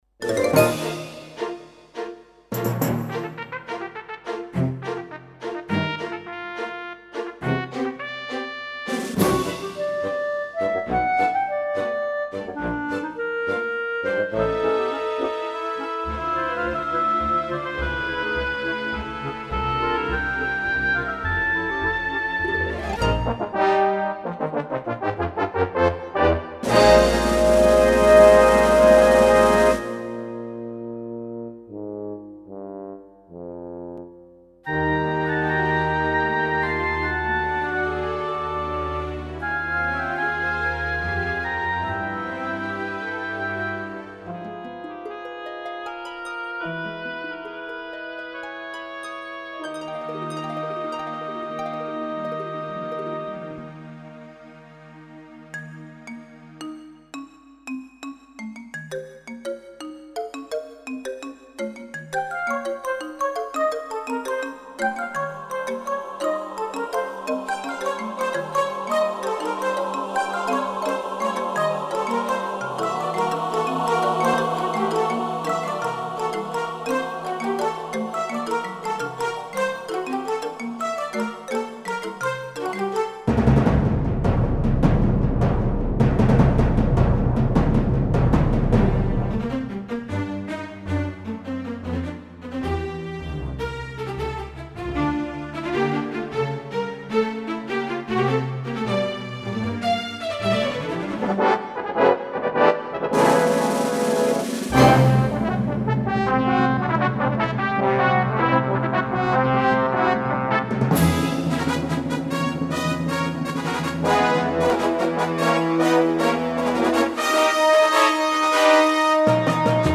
Orchestra module (1995)
demo factory demo 3